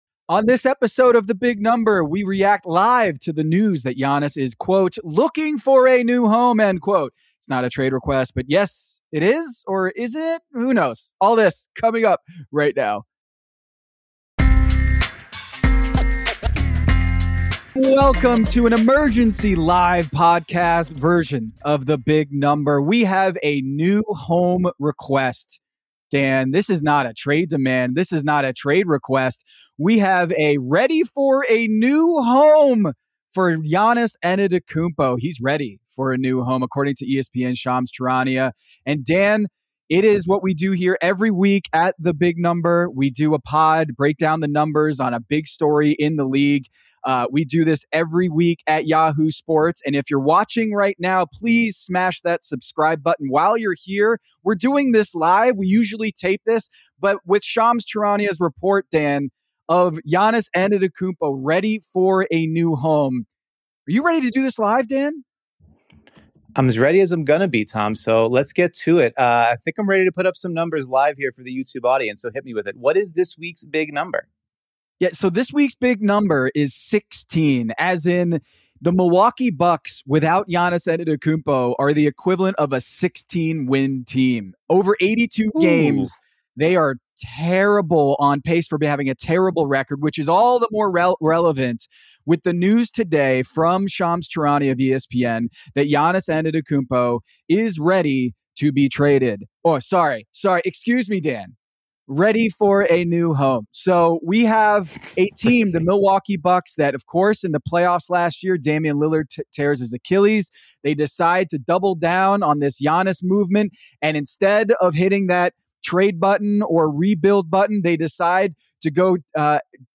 It's an emergency live podcast for today’s Big Number!